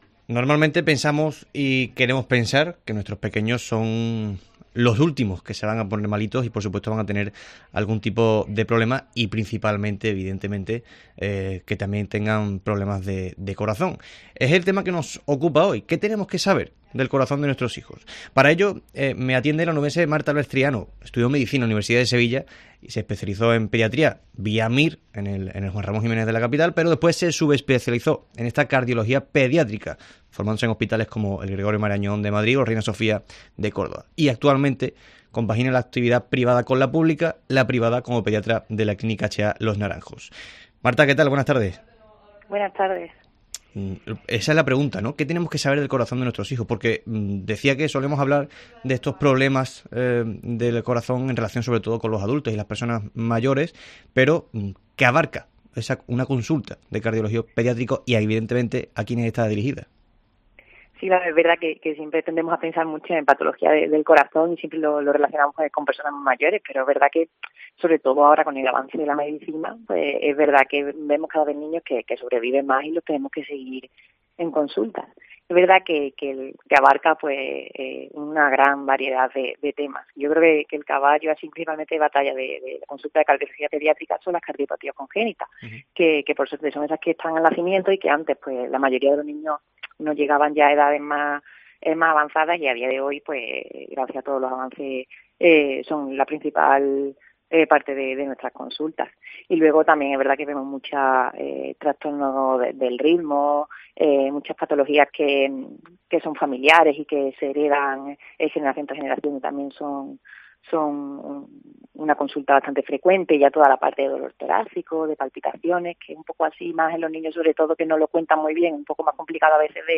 doctora especializada en Cardiología Pediátrica